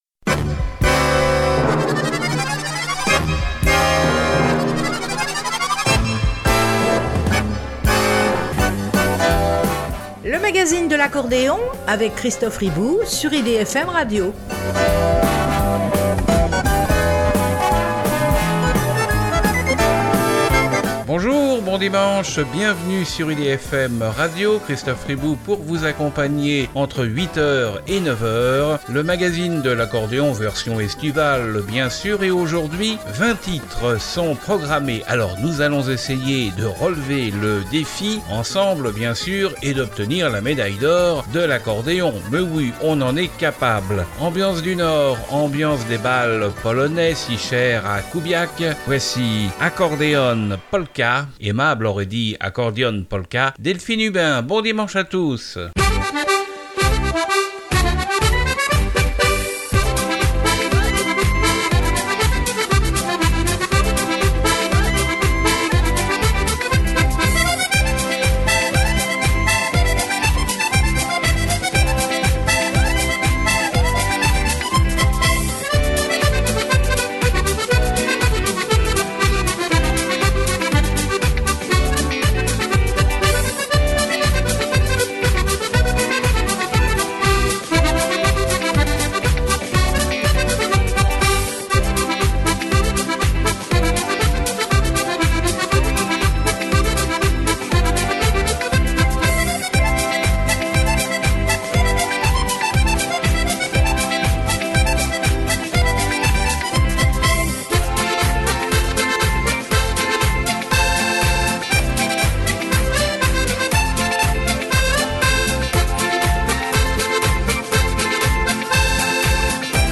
Invités, Reportages, Rubriques, Nouveautés….. Retrouvez toute l’ACTU de l’Accordéon.